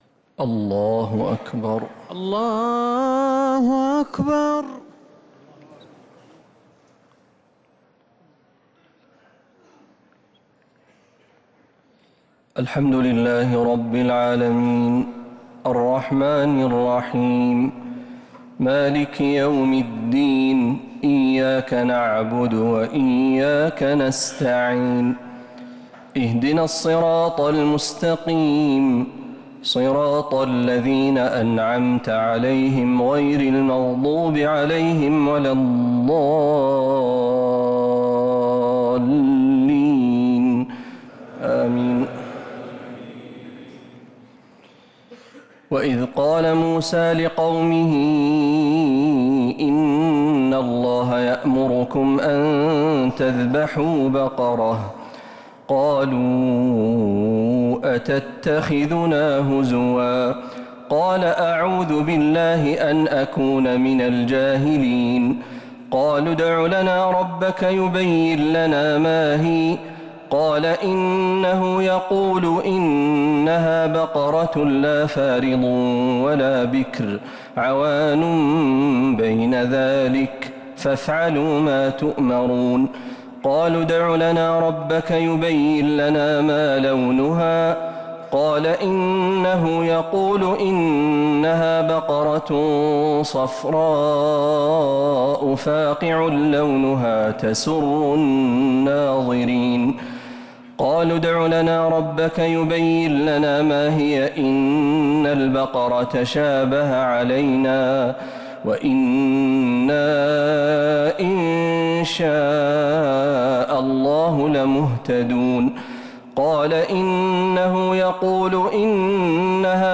تراويح ليلة 1 رمضان 1447هـ من سورة البقرة (67-105) | Taraweeh 1st night Ramadan 1447H > تراويح الحرم النبوي عام 1447 🕌 > التراويح - تلاوات الحرمين